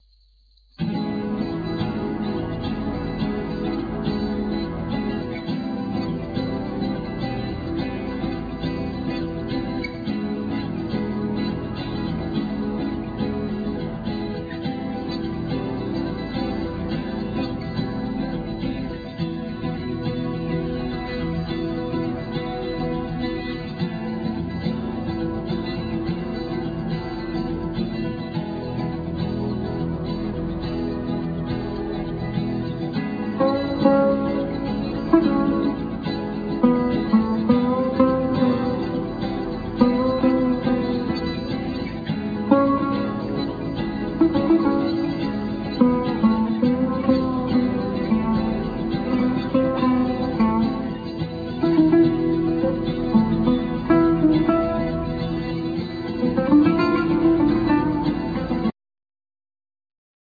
Kaval
Percussions
Keyboards
Drums
Guitar,Bass,Synthsizer programming
Vocals
North Indian flute